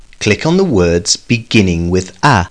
clicka.mp3